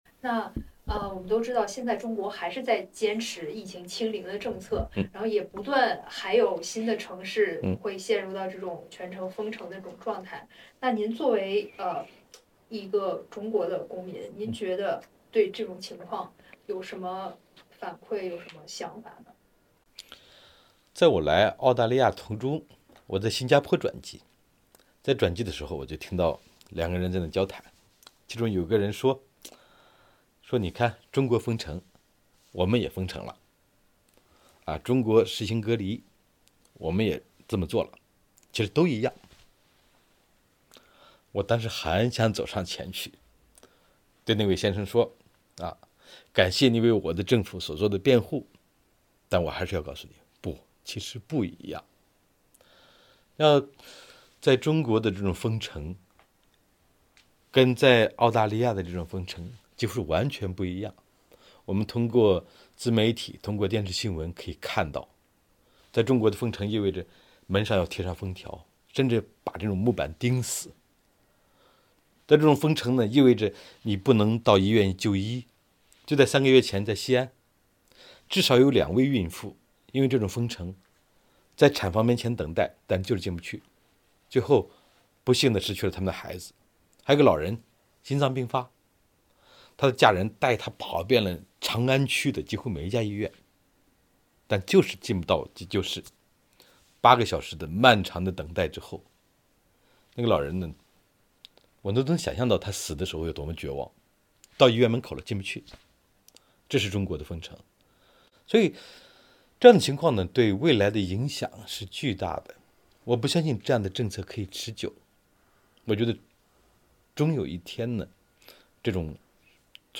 慕容雪村在接受采访时提到书中最令他触动的是一个医院清洁工的故事。